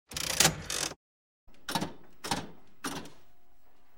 10. Звук механического игрового автомата — потянули за ручку и звук смены слотов
mehan-igravtomat.mp3